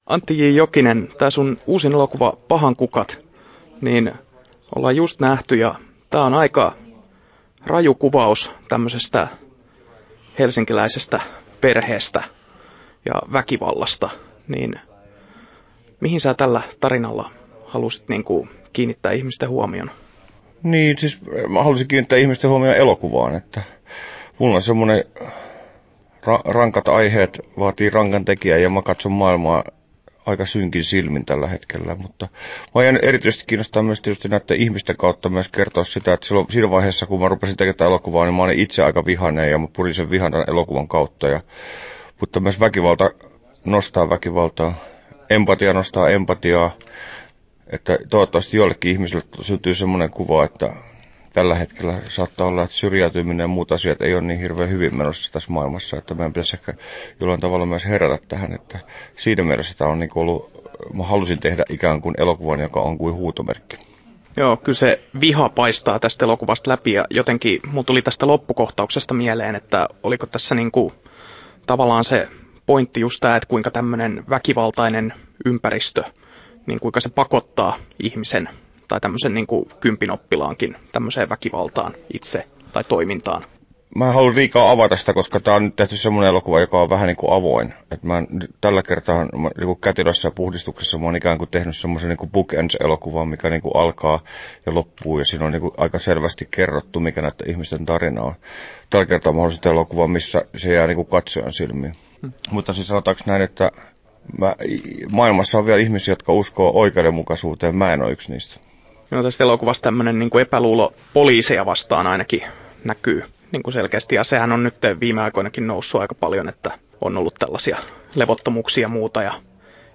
Haastattelussa Antti J. Jokinen Kesto: 7'48" Tallennettu: 21.9.2016, Turku Toimittaja